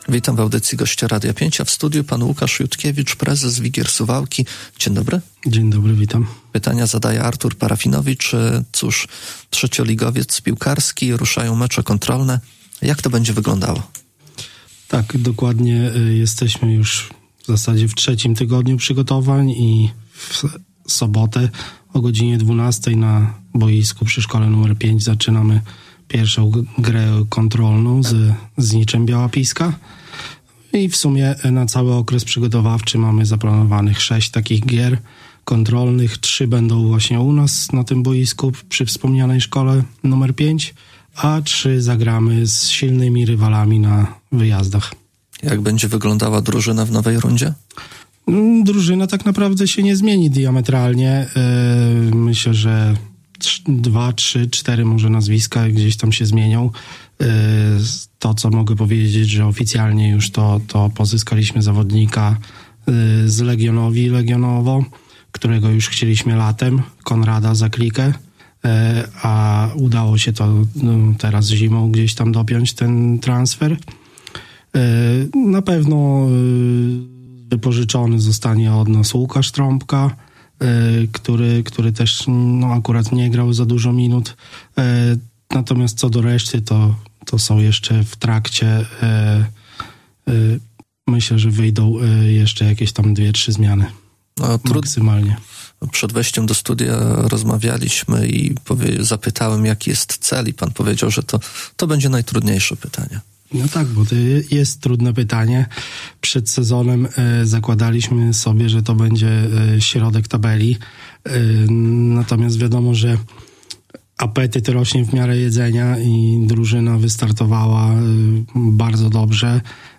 Poniżej pełne nagranie z gościem: